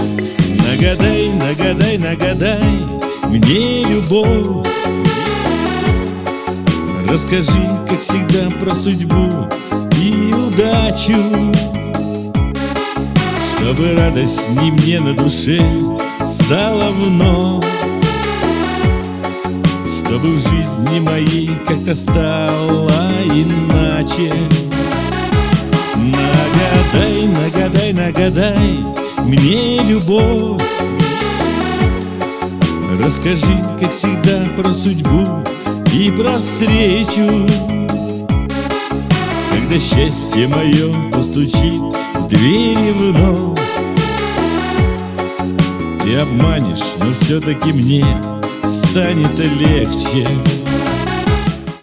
спокойные
русский шансон
цыганские